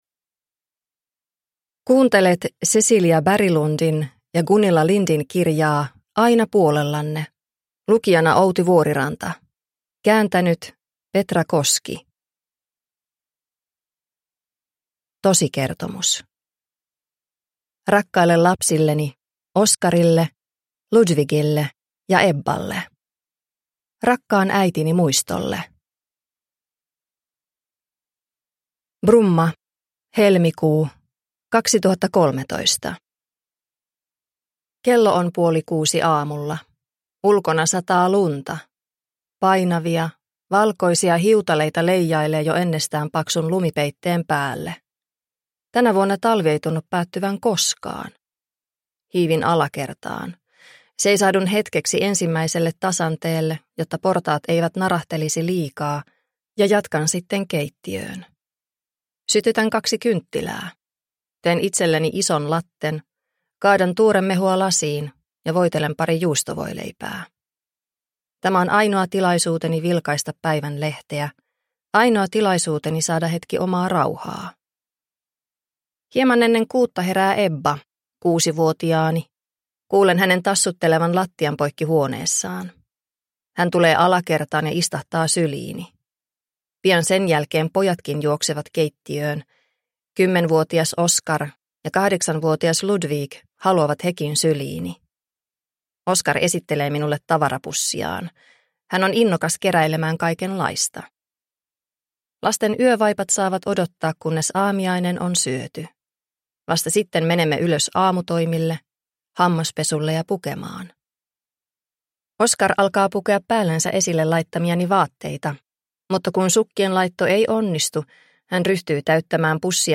Aina puolellanne – Ljudbok – Laddas ner